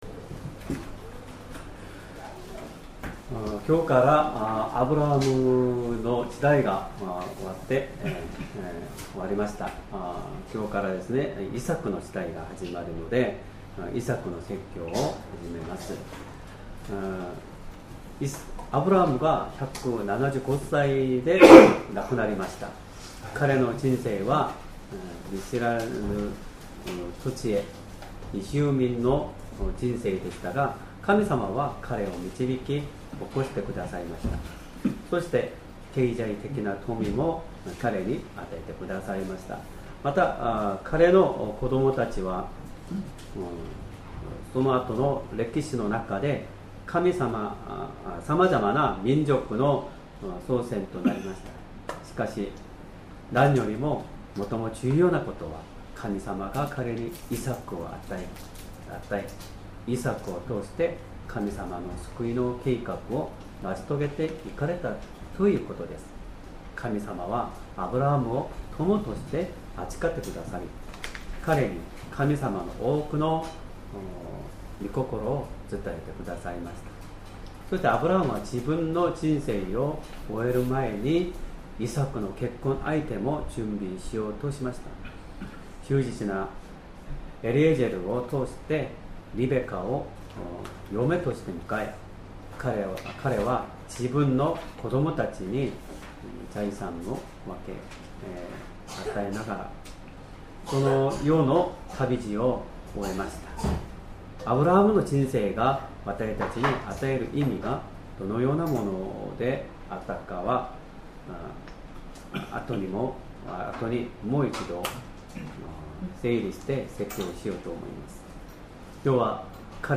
Sermon
2025年9月7日 主日礼拝 説教 「井戸を掘る信仰」 聖書 創世記 26章 12-33節 26:12 イサクはその地に種を蒔き、その年に百倍の収穫を見た。